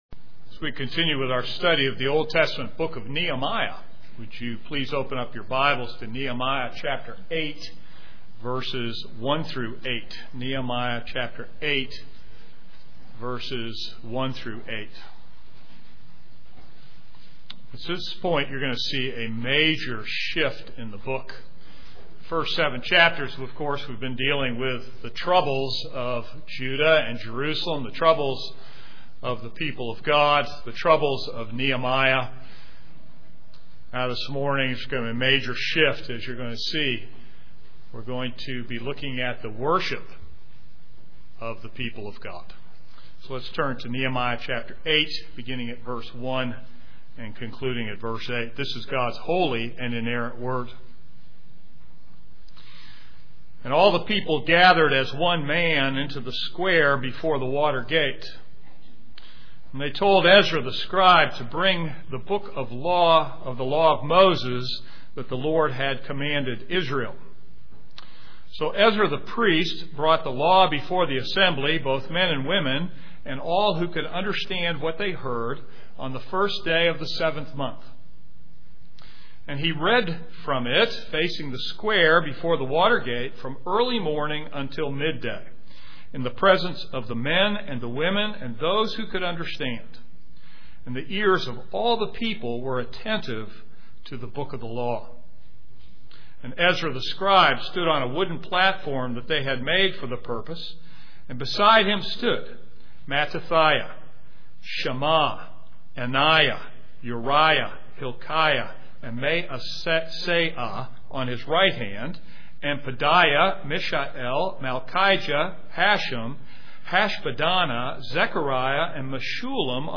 This is a sermon on Nehemiah 8:1-8.